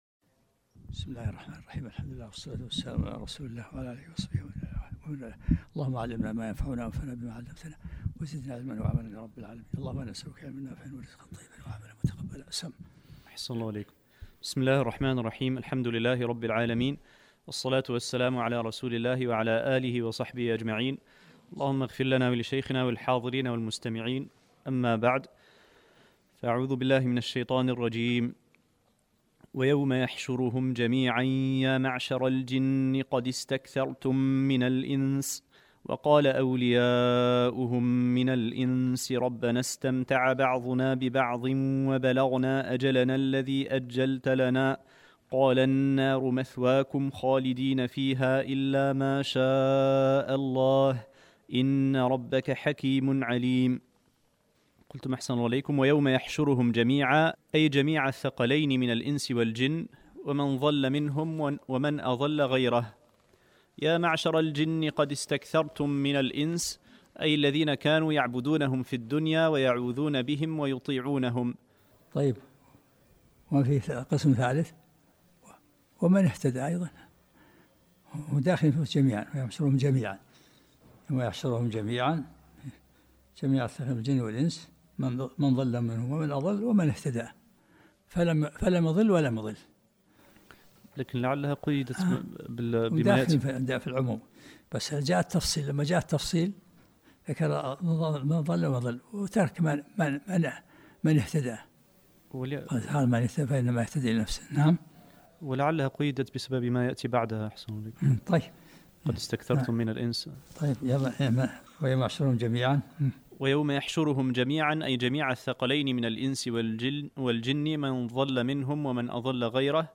الدرس السادس و العشرون من سورة الانعام